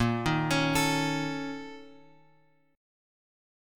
A# Minor Major 7th Double Flat 5th